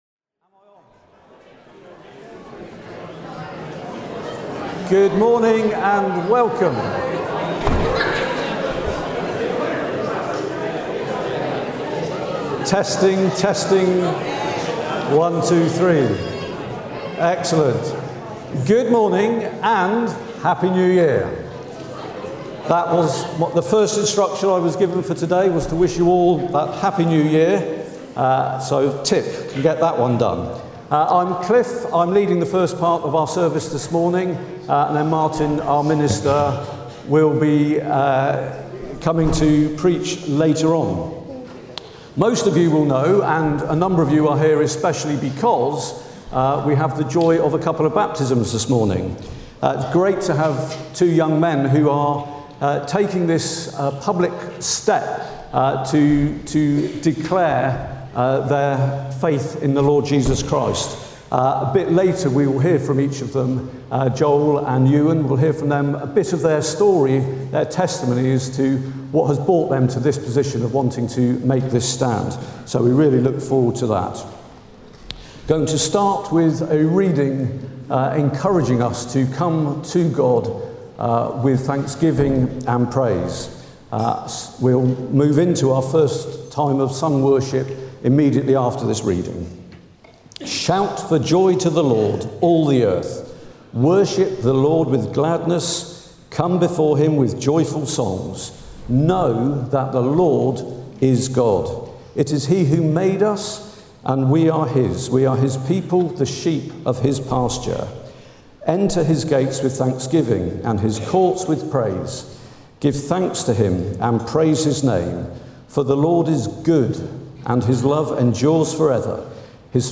4 January 2026 – Morning Baptismal Service
Service Type: Morning Service